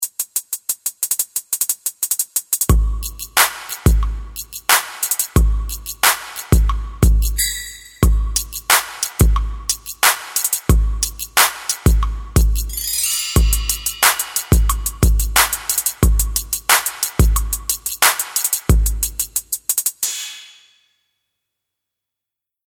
| hip hop drum sequence |